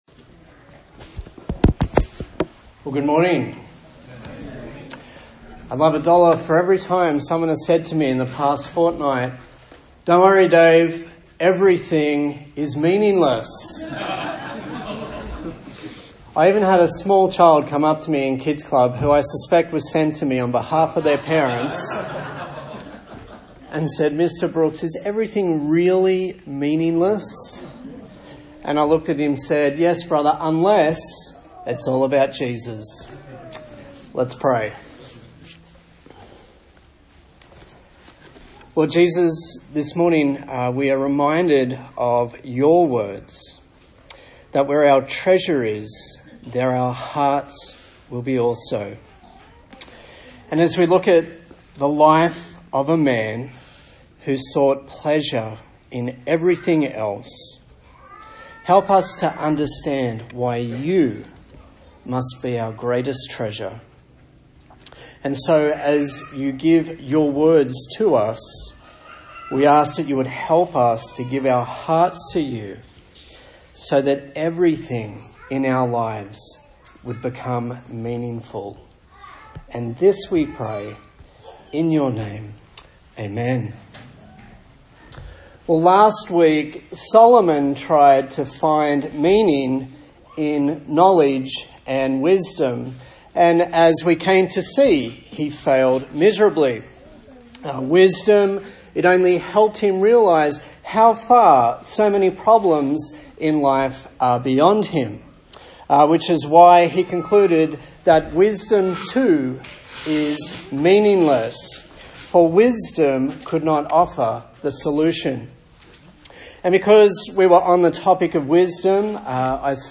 A sermon in the series on the book of Ecclesiastes
Service Type: Sunday Morning